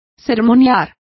Complete with pronunciation of the translation of sermonizing.